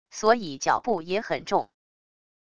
所以脚步也很重wav音频生成系统WAV Audio Player